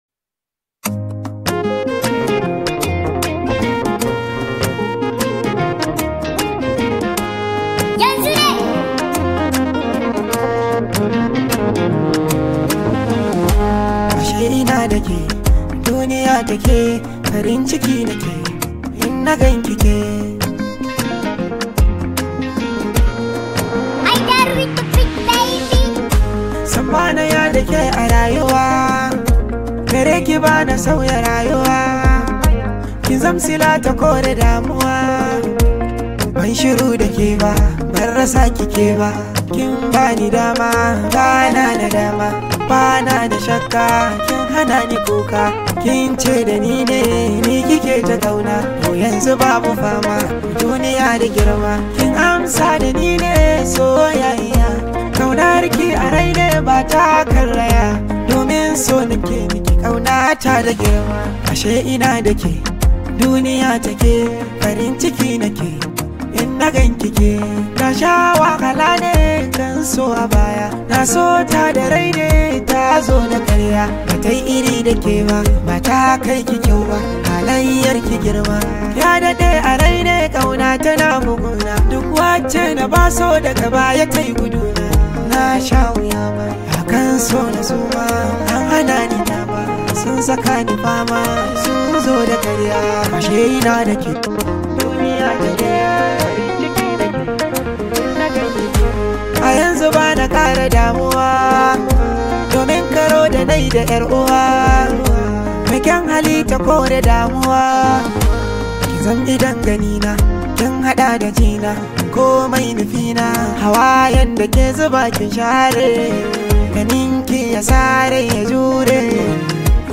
highly celebrated Hausa Singer